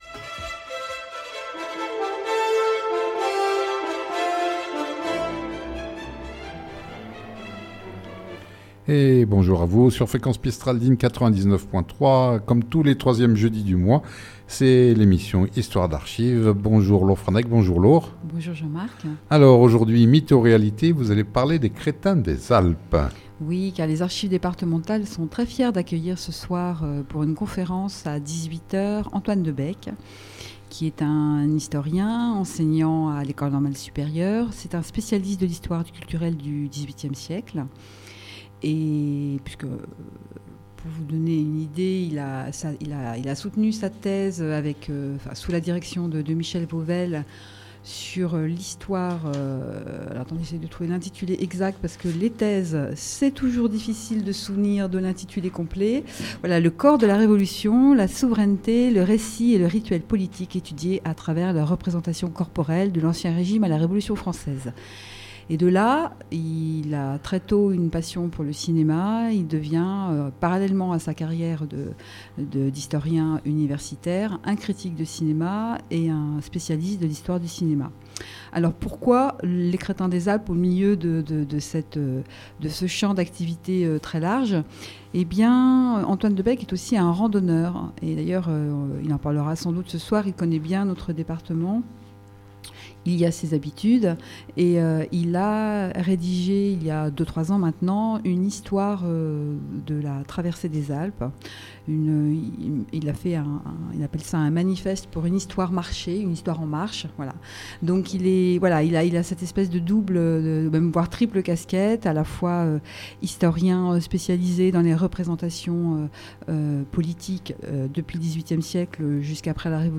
Cette émission sur fréquence mistral à Digne, a lieu tous les 3èmes jeudi du mois en direct de 9h15 à 10h